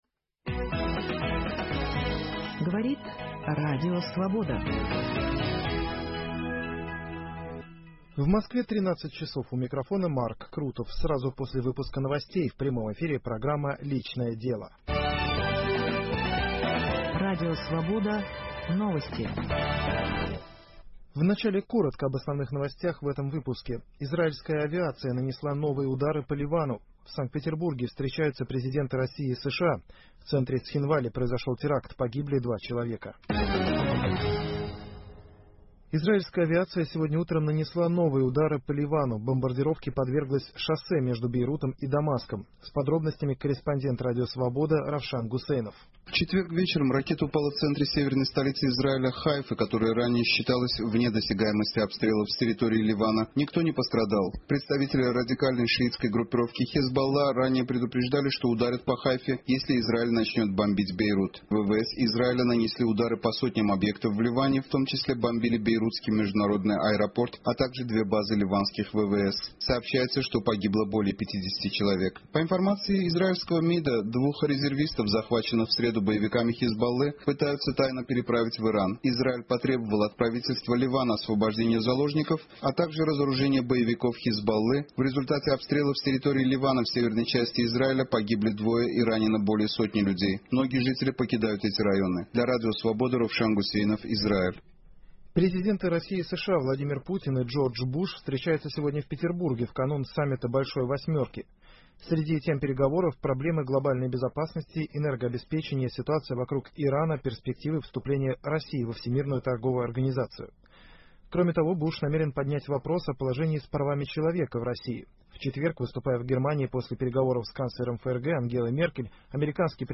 Гости студии